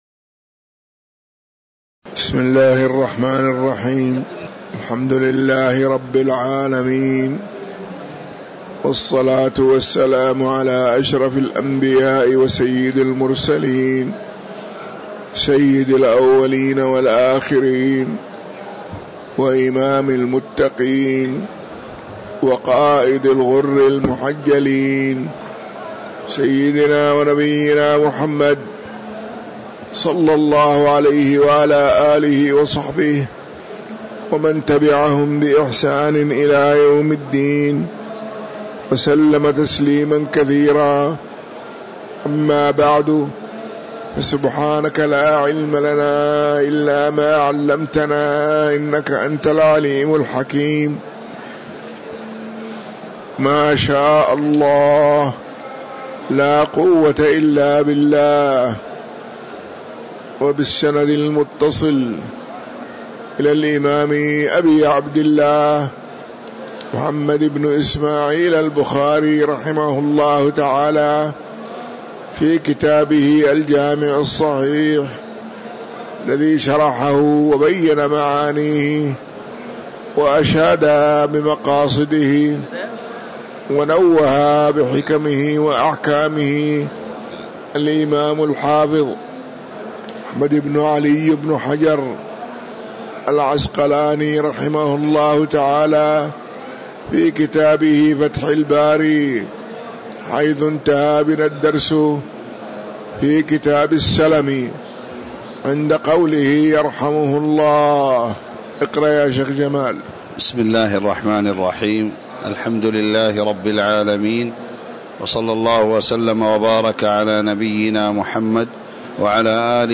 تاريخ النشر ١٣ جمادى الأولى ١٤٤٥ هـ المكان: المسجد النبوي الشيخ